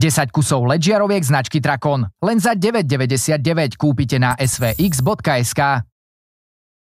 Hlas do reklamy (voiceover)
(vyčistenie nahrávky od nádychov rôznych ruchov a zvuková postprodukcia je samozrejmosťou)